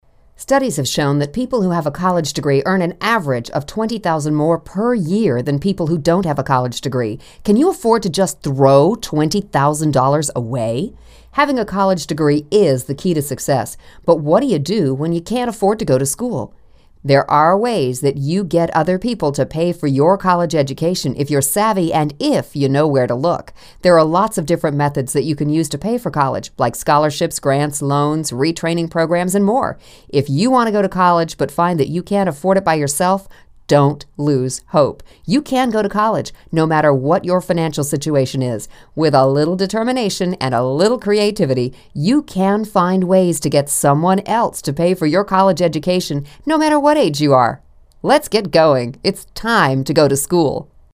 Free College Money Audio Book